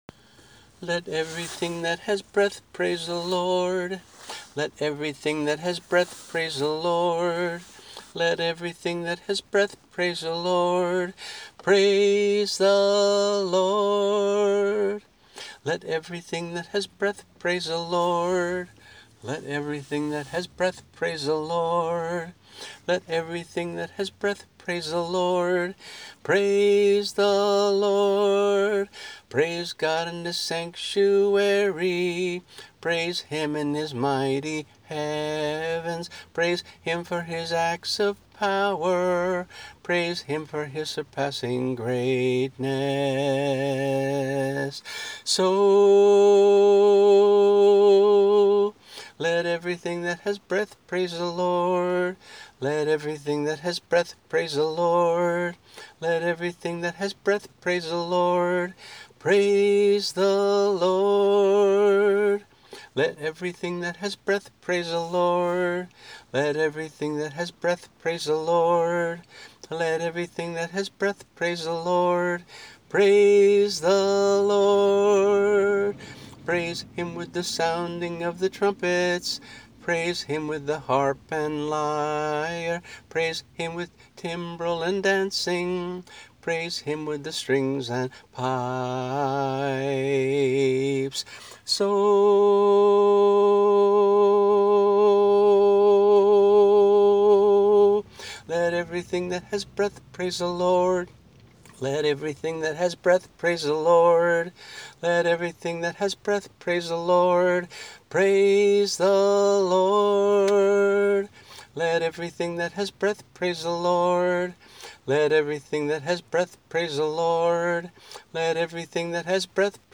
[MP3 - voice and guitar]